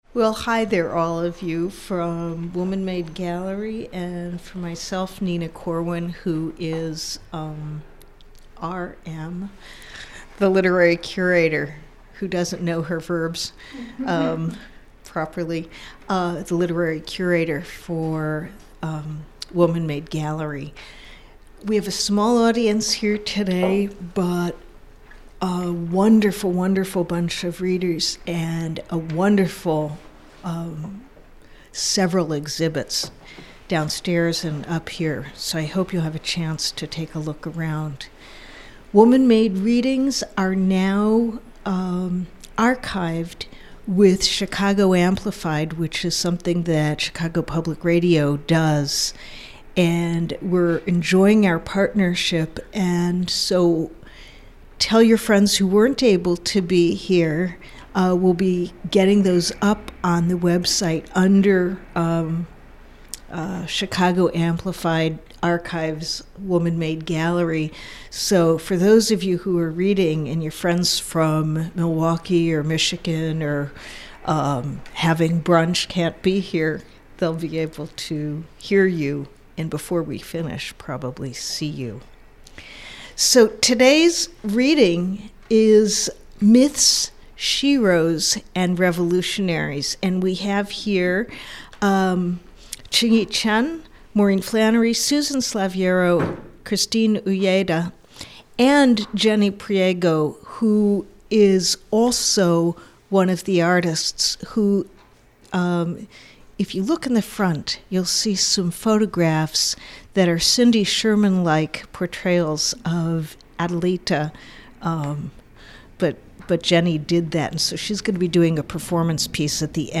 Woman Made Gallery Poetry Reading Series recorded Sunday, 2010-10-03